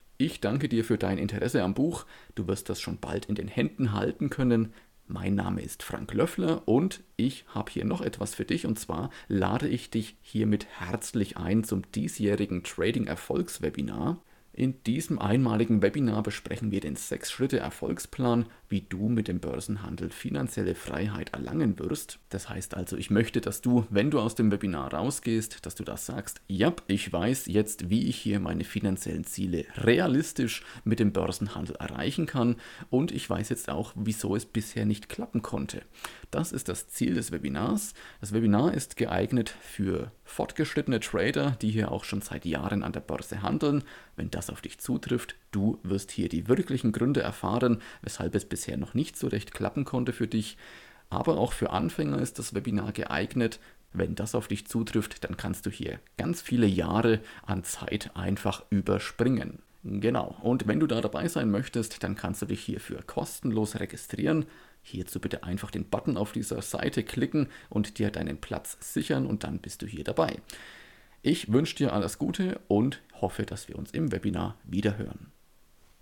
Webinar-Danke-Audio-Sprachnachricht.mp3